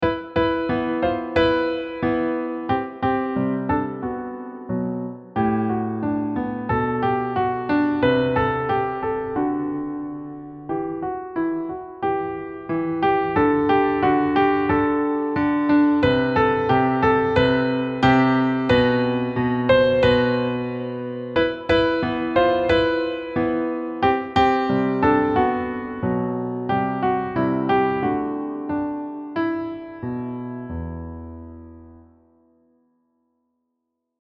Key: E Natural Minor with accidentals
Time signature: 4/4 (BPM = 180)
Level: Late Elementary
• Lyrical playing + expressive dynamics